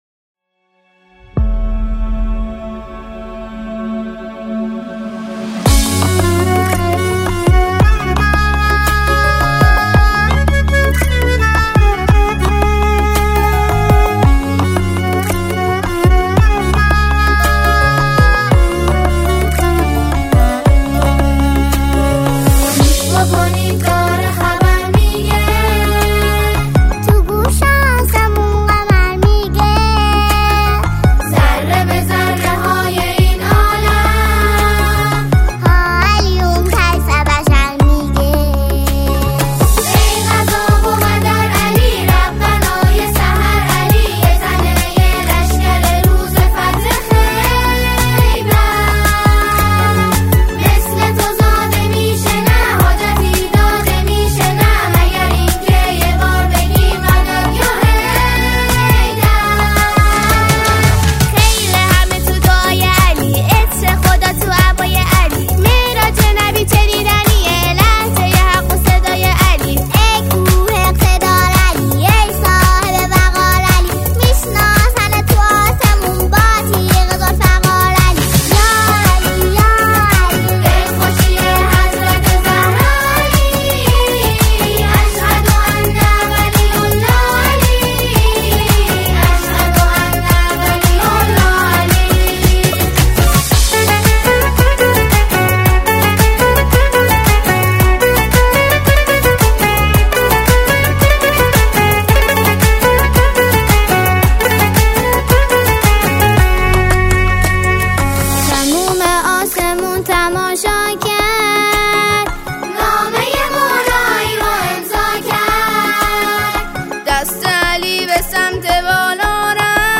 به مناسبت عید غدیر خم
به صورت جمع خوانی